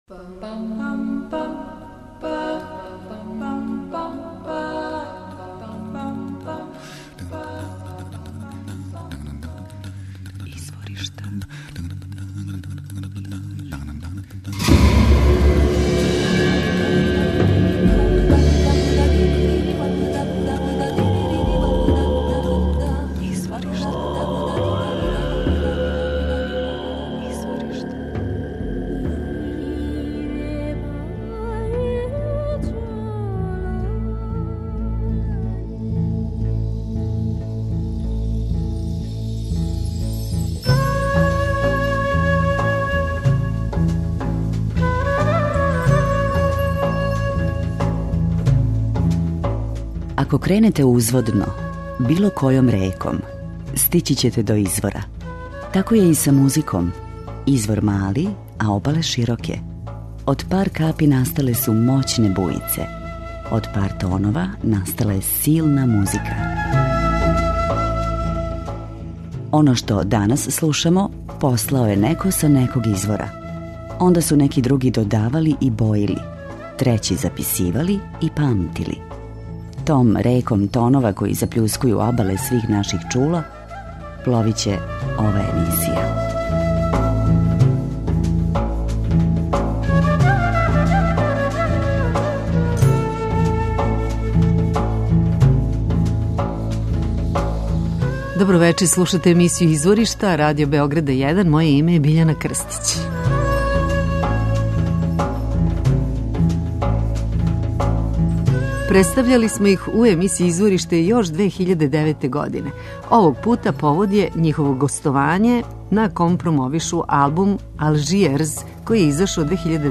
акустични фолк-џез оркестар